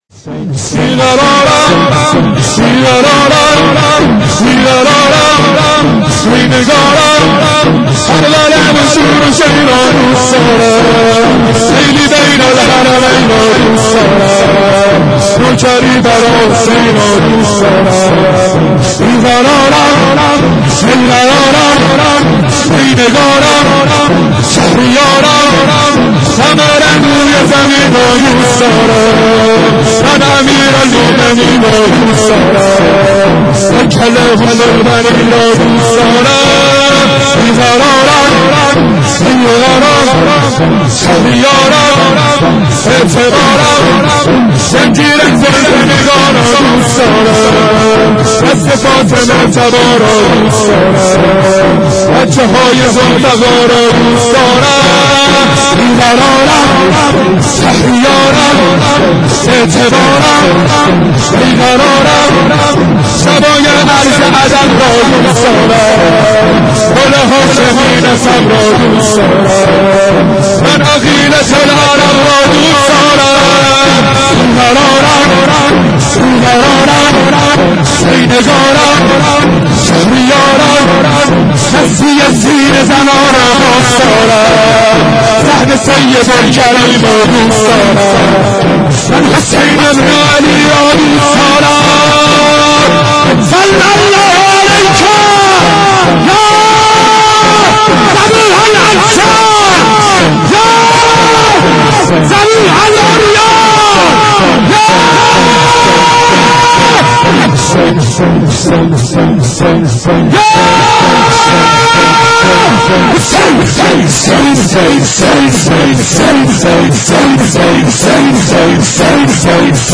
شور
شب 21 رمضان 92